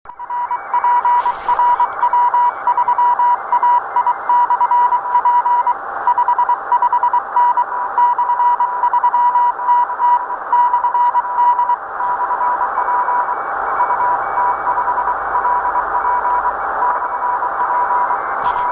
Это в поле, точнее в лесу, приём на Р-143.